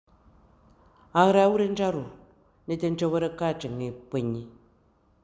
Lecture et prononciation